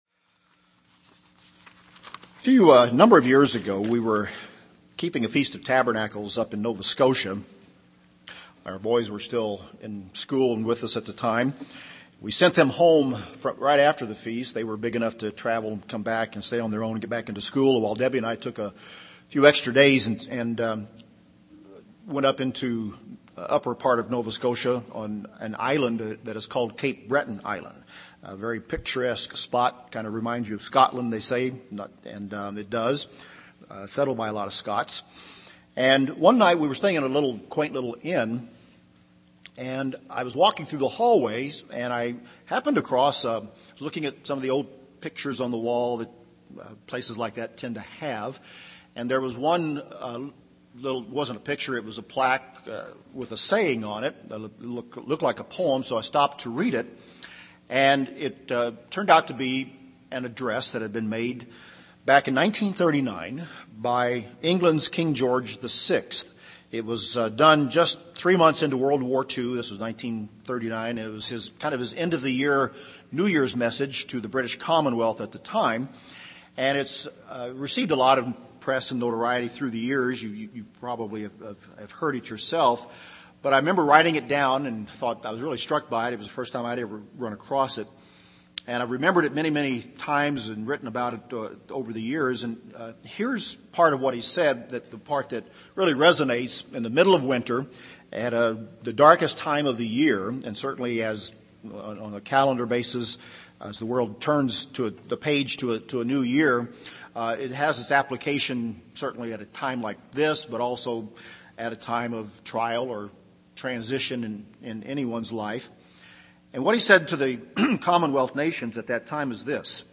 Stand Still and See the Salvation of God UCG Sermon Transcript This transcript was generated by AI and may contain errors.